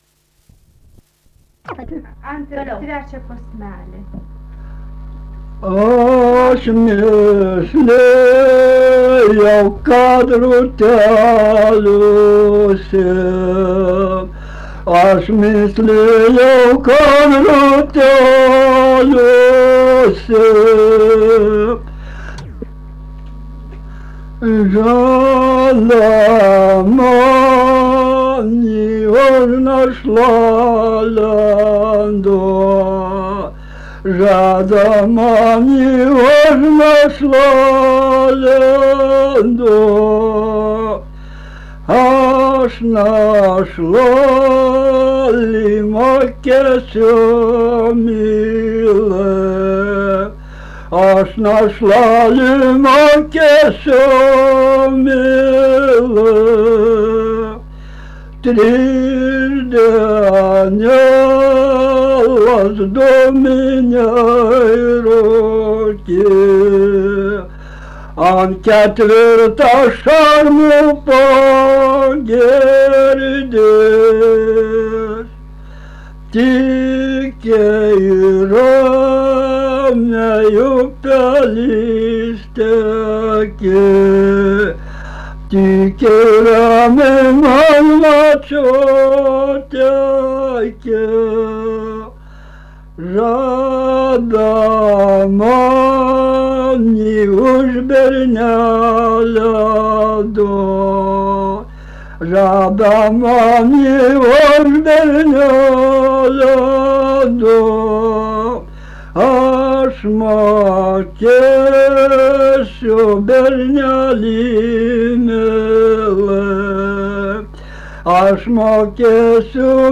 Dalykas, tema daina
Erdvinė aprėptis Krapiškis
Atlikimo pubūdis vokalinis